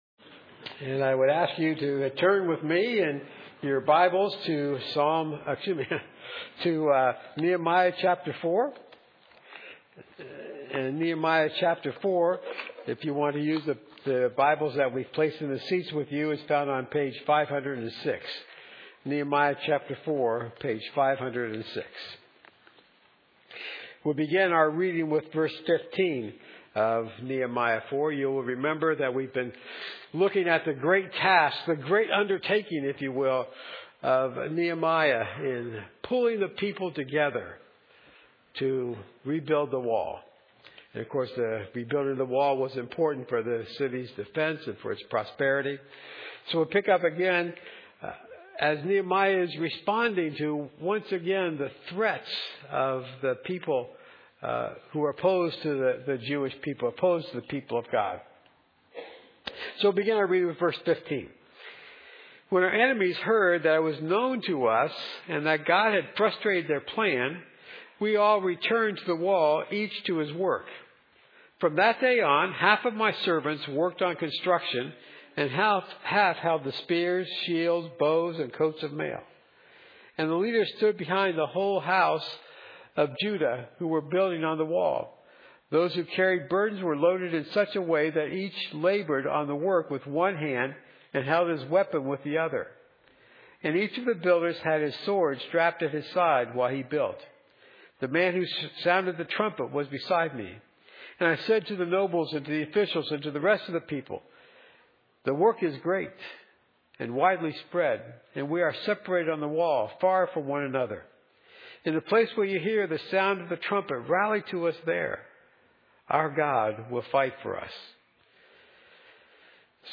Nehemiah 5:14-19 Service Type: Morning